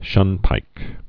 (shŭnpīk)